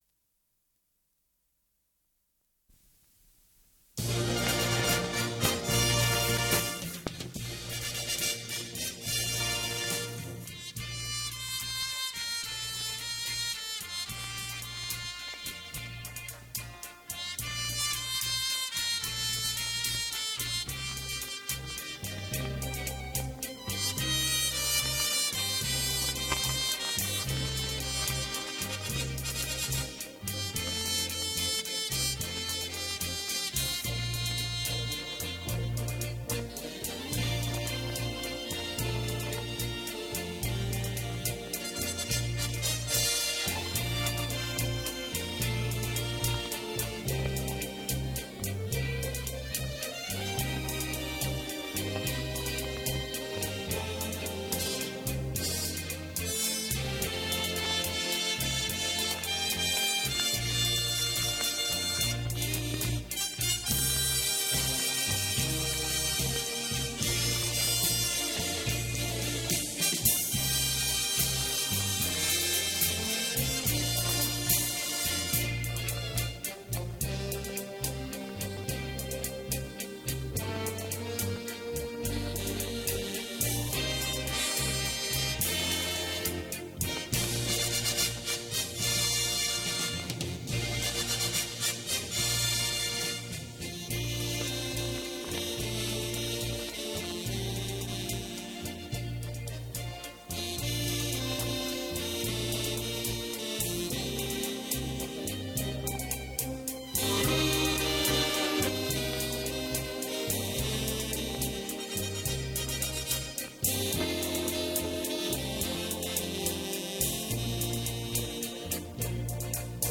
Инструментальный вариант песни.
Моно дубль.